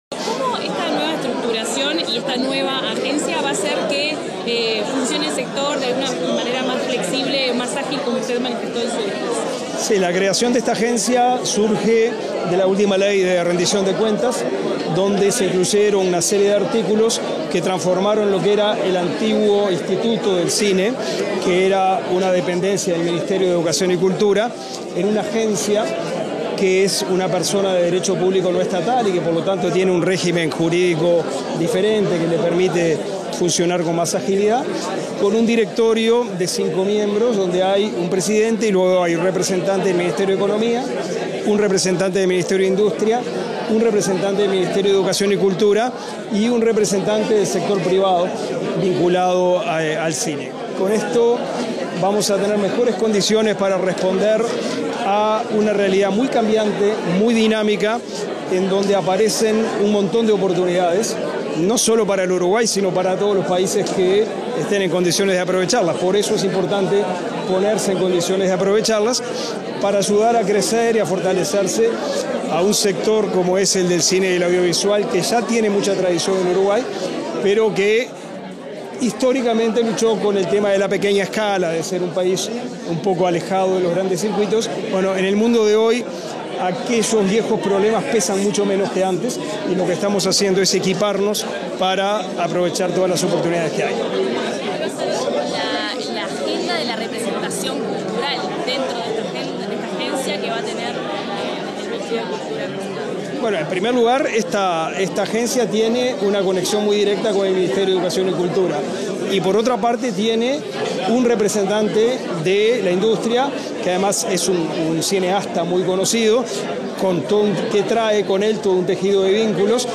Declaraciones del ministro de Educación y Cultura, Pablo da Silveira
Declaraciones del ministro de Educación y Cultura, Pablo da Silveira 10/02/2023 Compartir Facebook Twitter Copiar enlace WhatsApp LinkedIn Tras la ceremonia de asunción de las nuevas autoridades de la ACAU, este 10 de febrero, el ministro de Educación y Cultura, Pablo da Silveira, realizó declaraciones a la prensa.